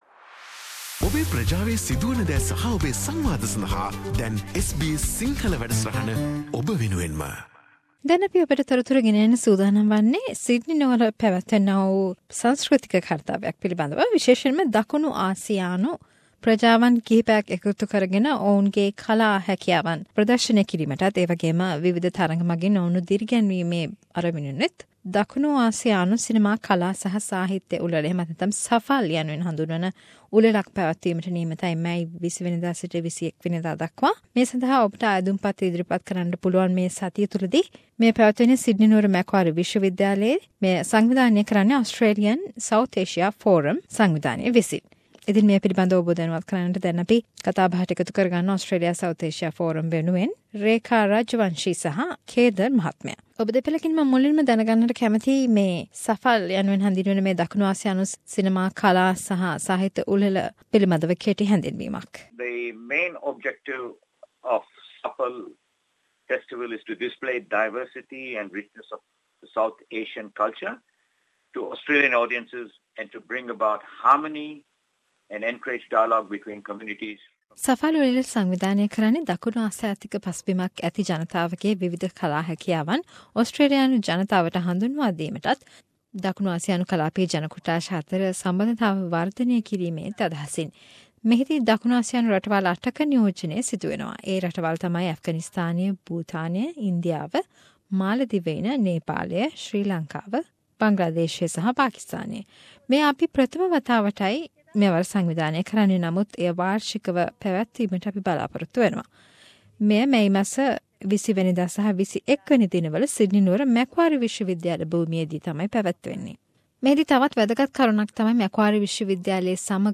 A chat about SAFAL - a festival of arts and culture for South Asians